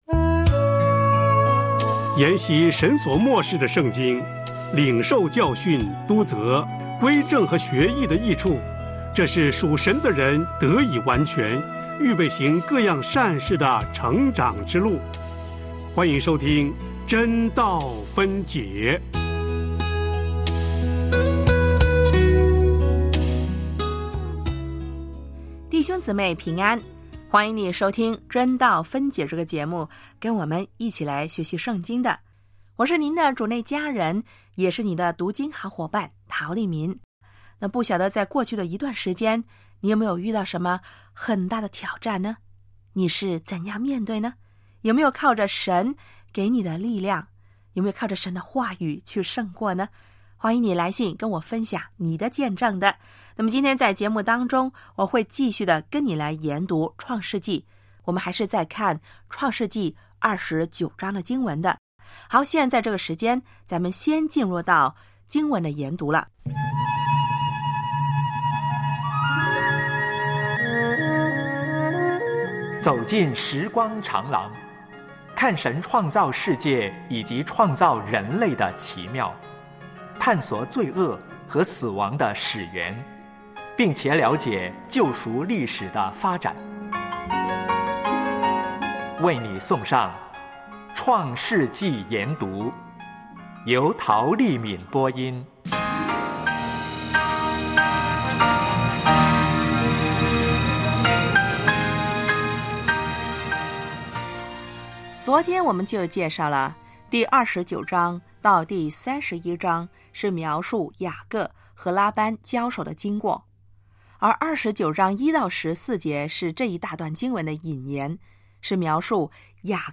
创世记（37）雅各和拉班交手（创29:1-30）（讲员